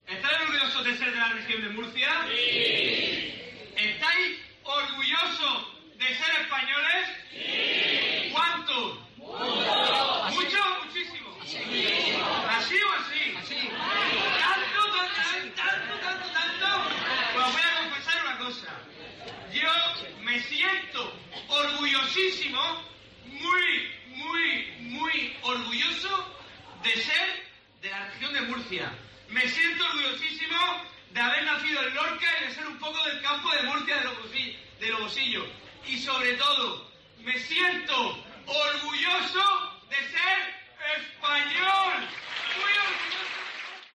El presidente de Murcia, durante un mitin: ¿Estáis orgullosos de ser de Murcia?